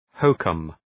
Προφορά
{‘həʋkəm}